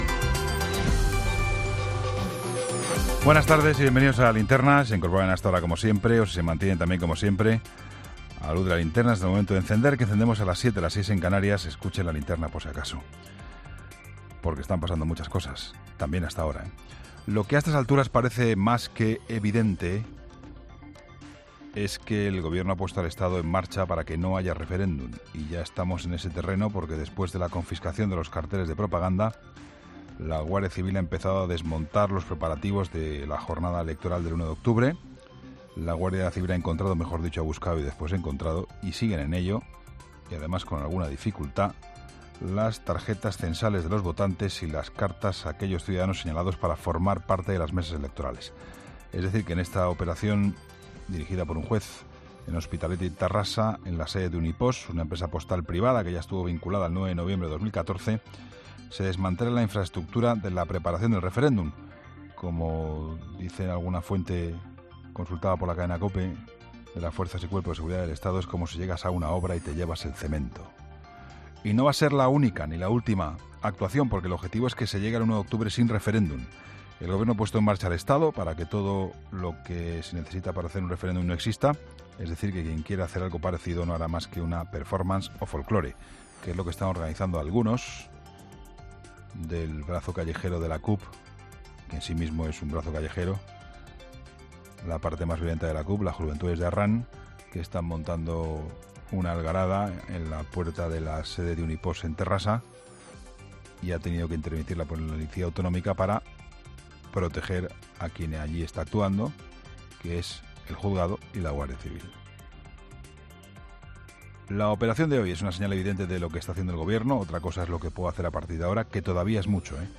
La crónica